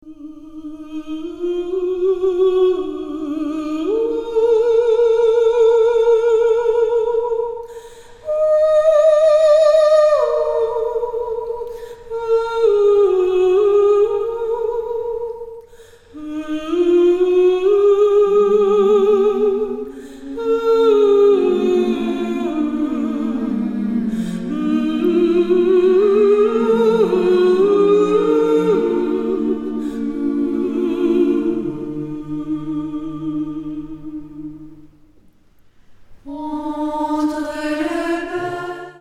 Chants de Noël
chantent à 4 voix